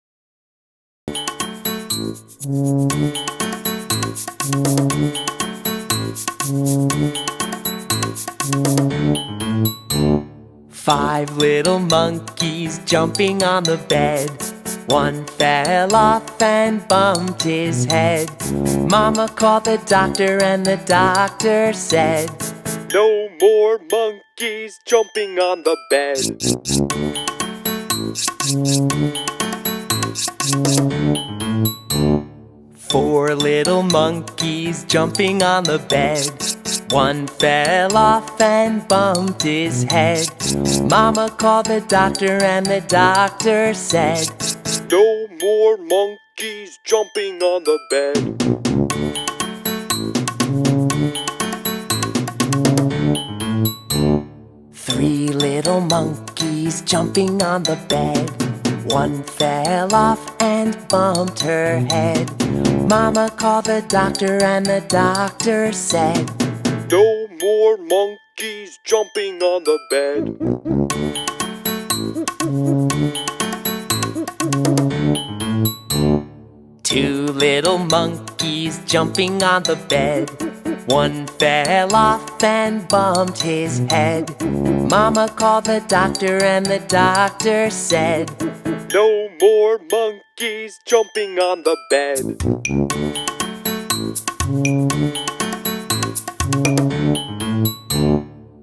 song_monkeys.wav